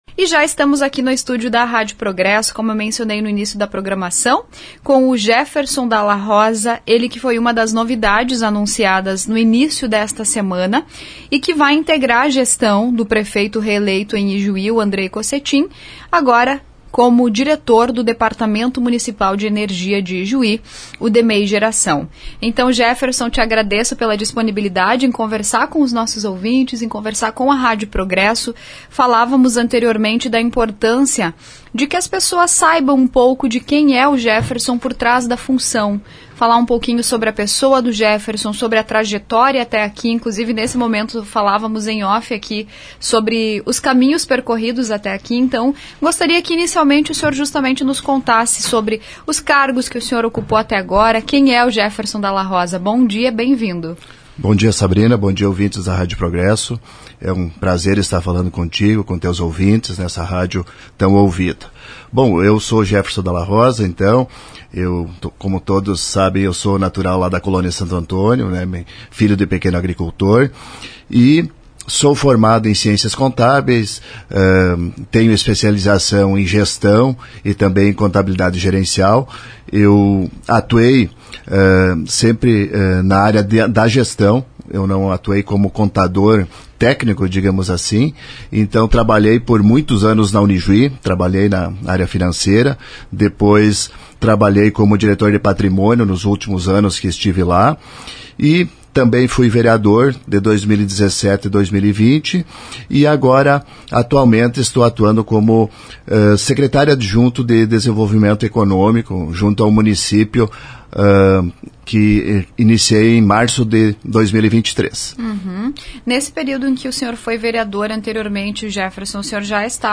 Em recente entrevista à RPI, compartilhou um pouco de sua trajetória.
ENTREVISTA-JEFERSON-DALA-ROSA.mp3